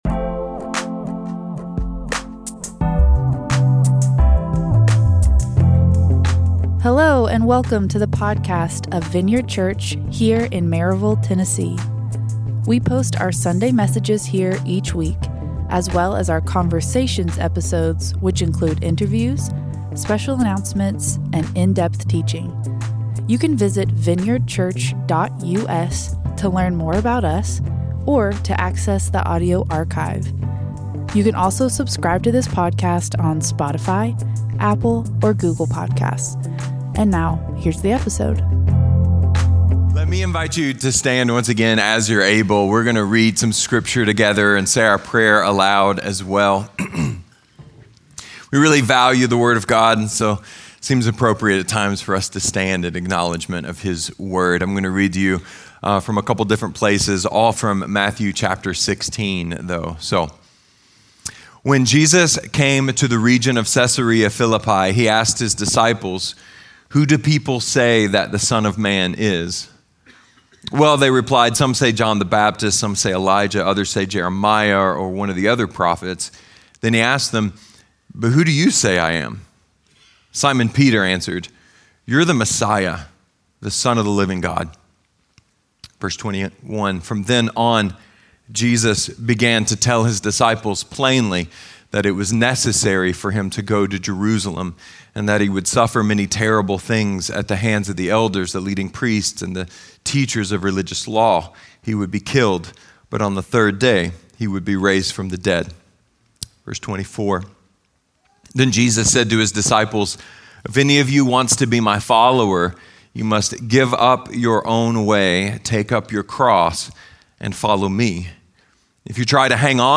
A message from the series "Easter 2024."